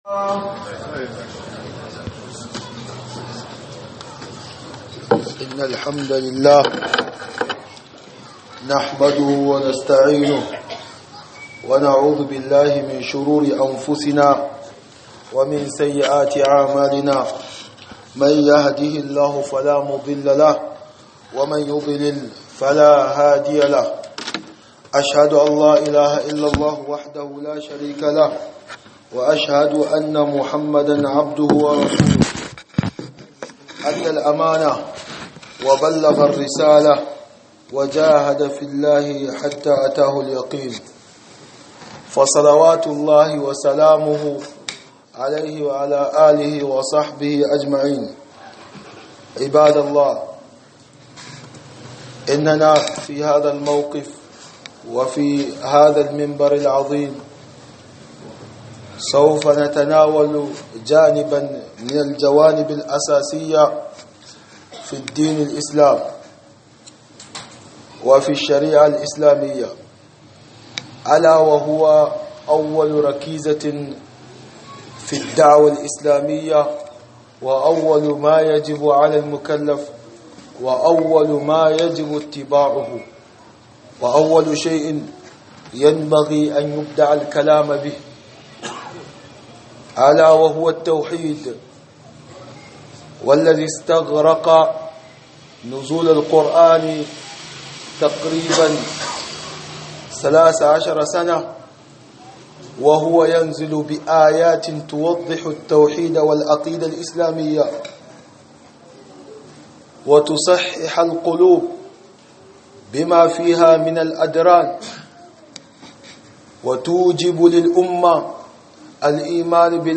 خطبة بعنوان التوحيد أول واجب على الأمة